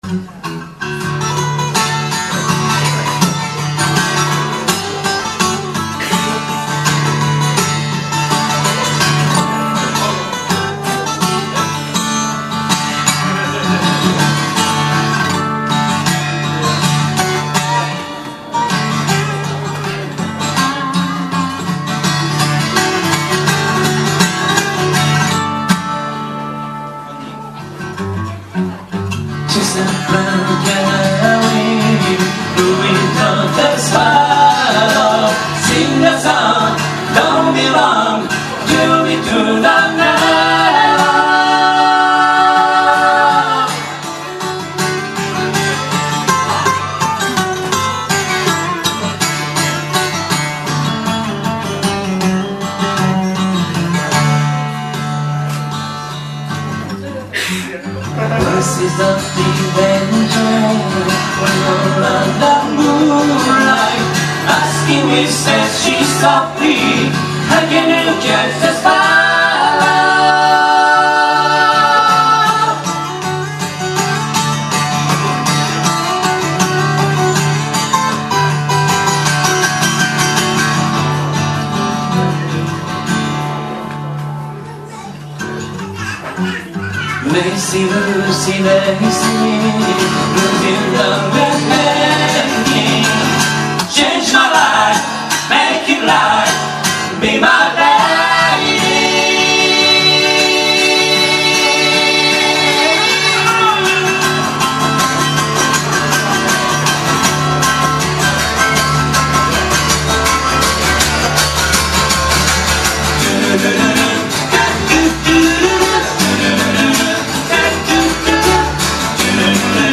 東京都府中市　「ライブシアターFlight」
社会人バンド・コンベンション